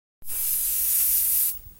air freshener spray.ogg